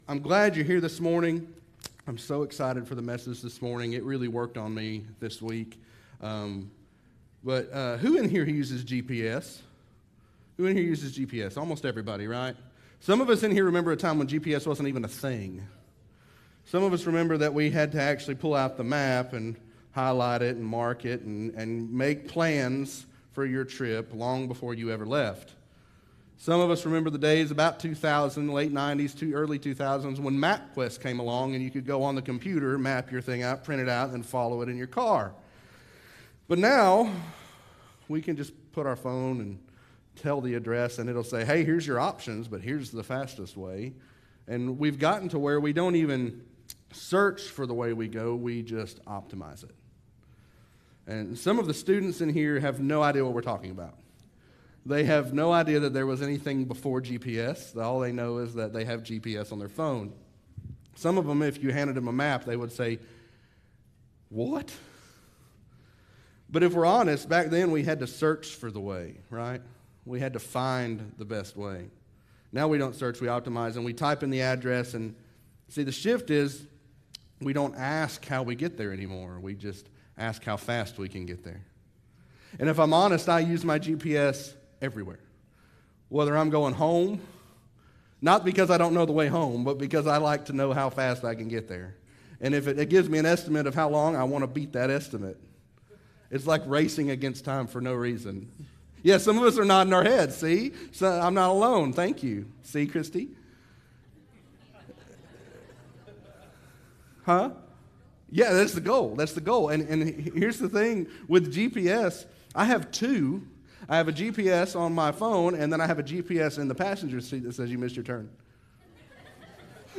Sermons | Mount Vernon Baptist Church